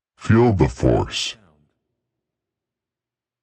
“Feel the force” Clamor Sound Effect
Can also be used as a car sound and works as a Tesla LockChime sound for the Boombox.